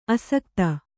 ♪ asakta